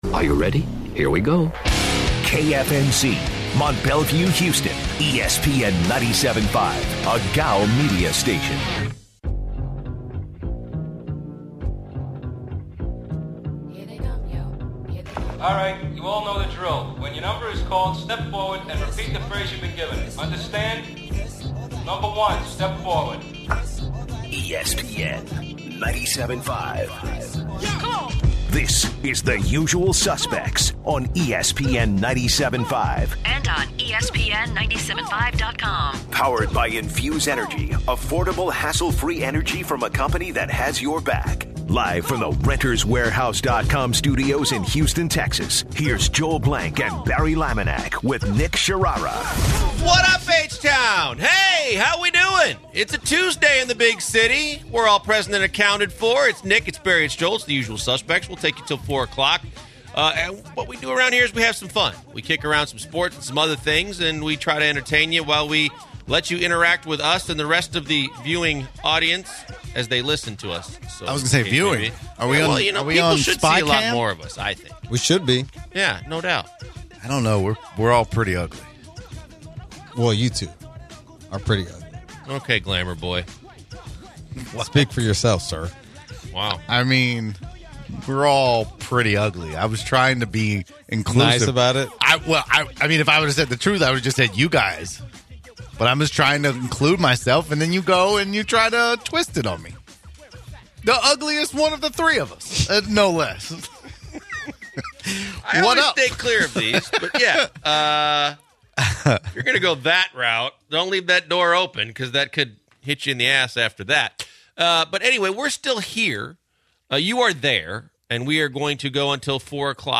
The guys end the hour in a heated debate with listeners about the DeAndre Jordan trade for Clint Capela that did not happen.